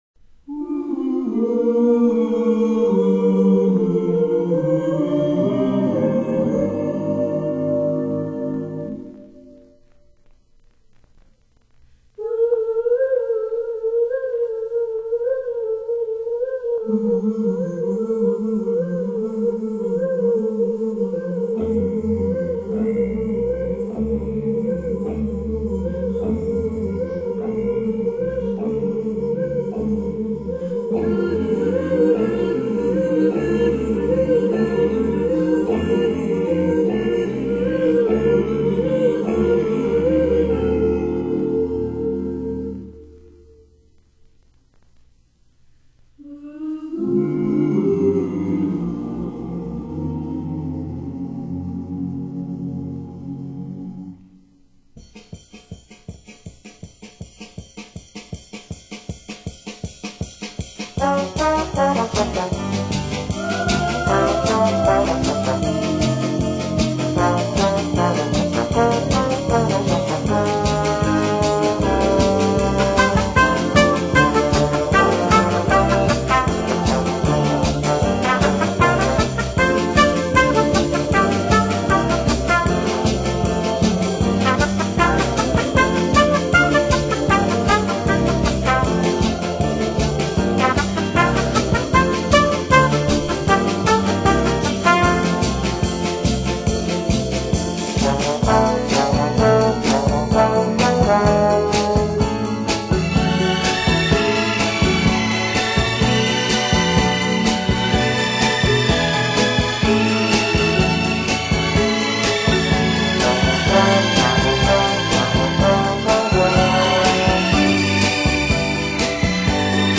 【类型】Jazz, Light Music
这是一张36年前的经典圣诞专辑，小型铜管乐队加轻快的小喇叭，演奏经典的曲目。